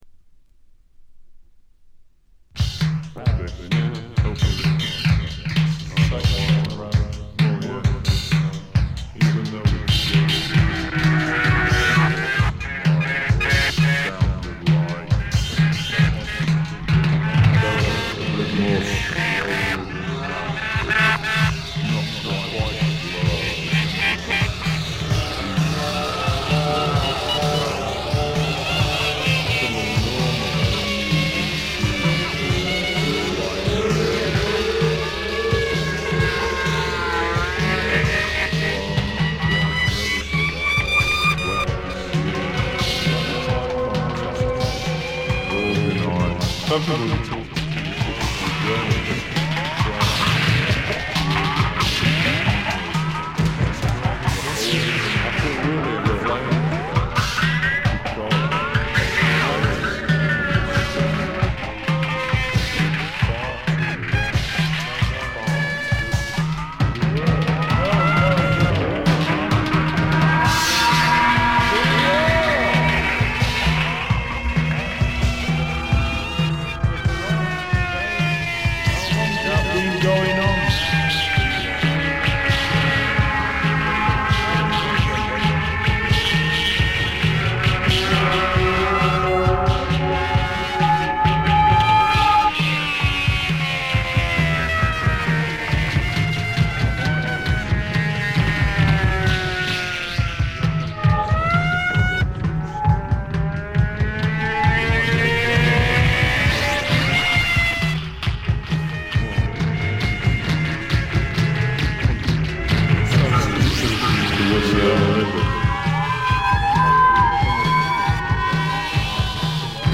試聴曲は現品からの取り込み音源です。
Electronics, Tape
Drums